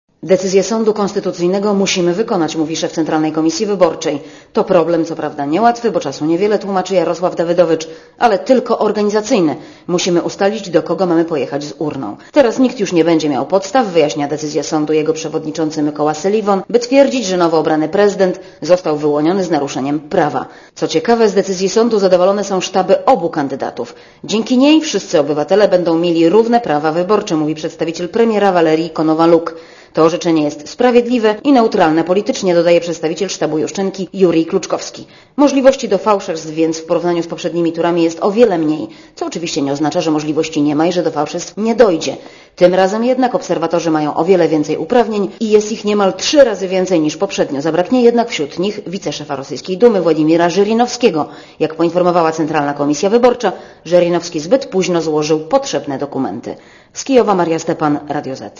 Korespondencja z Kijowa